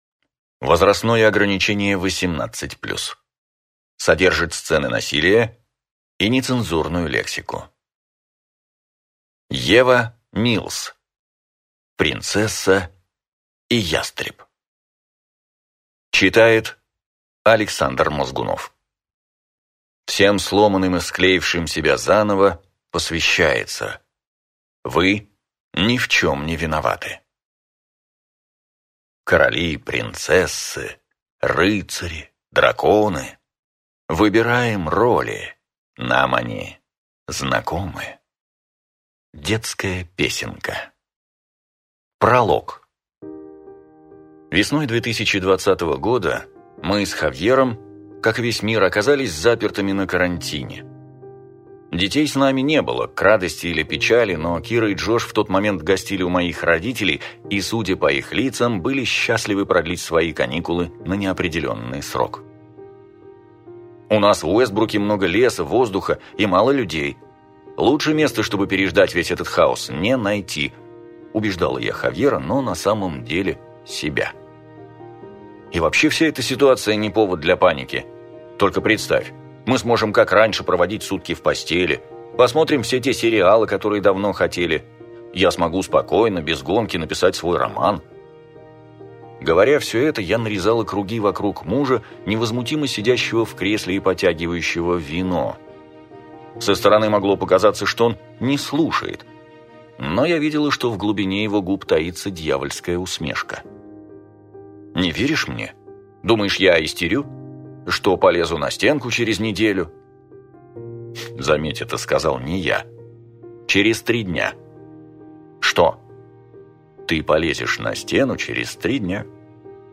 Аудиокнига Принцесса и Ястреб | Библиотека аудиокниг